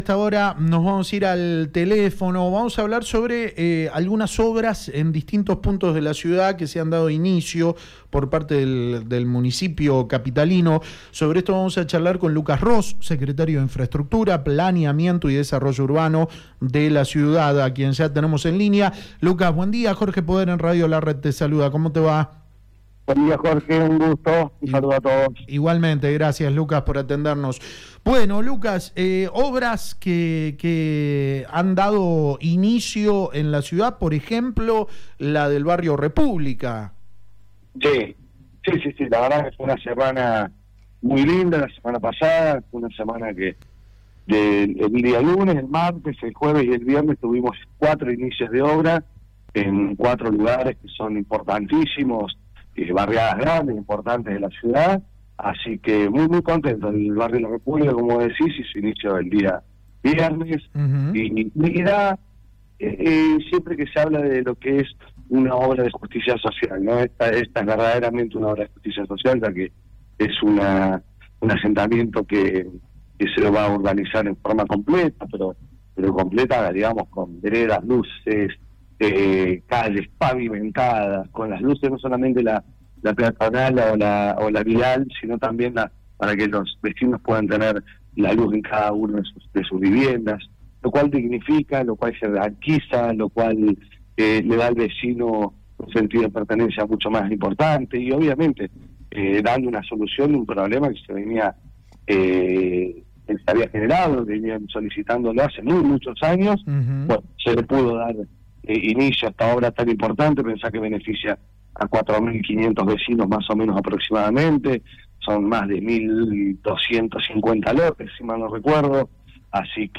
“El día viernes dimos inicio, es una obra de justicia social, muy grande que llevara dos años de ejecución, se va a realizar absolutamente todo, luz, gas, veredas, lo cual dignifica a los vecinos y jerarquiza el barrio”, comentó Lucas Ros, secretario de Infraestructura, Planeamiento y Desarrollo Urbano de la Municipalidad de San Luis, en diálogo con La Red San Luis.